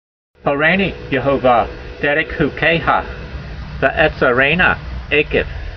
Sound (Psalm 119:33) Transliteration: ho ray nee ye hova dey reyk hu key ha , ve ' e tse' rey n ah ay' keyv Vocabulary Guide: Teach me Lord the way of your statutes and I shall guard it continually . Translation: Teach me Lord the way of your statutes and I shall guard it continually.